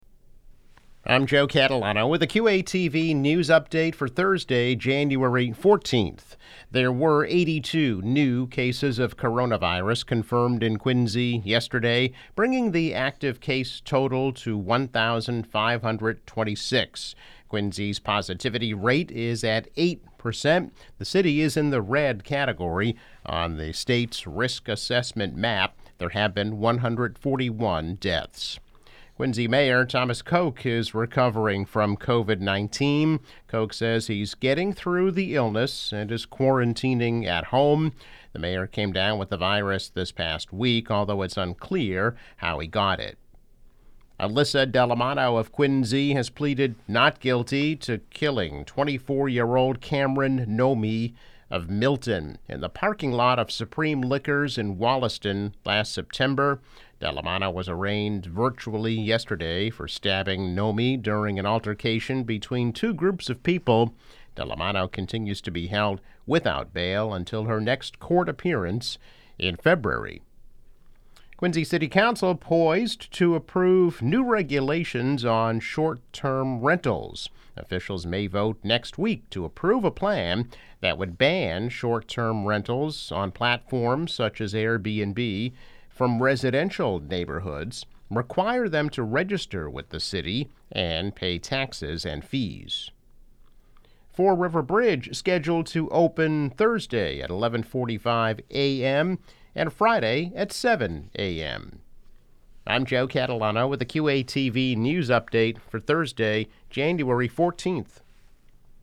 News Update - January 14, 2021